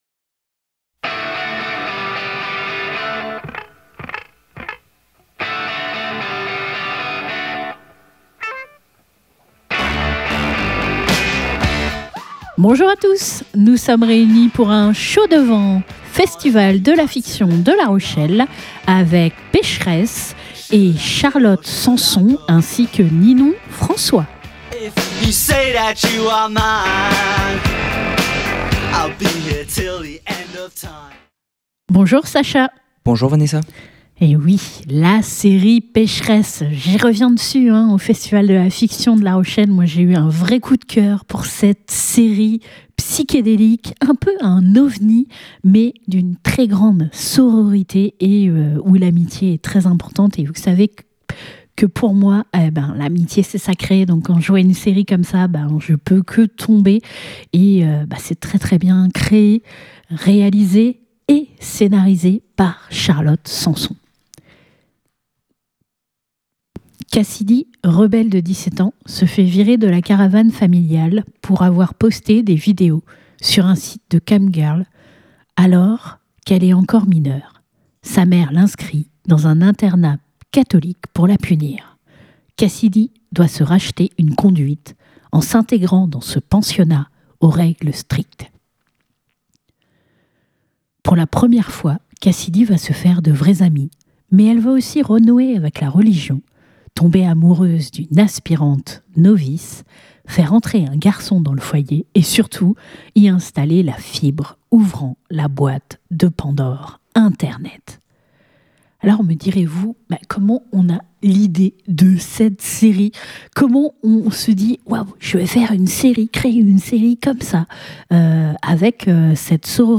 Et voici la dernière interview en duo que nous avons pu réaliser au Festival de la fiction de La Rochelle concernant l'époustouflante série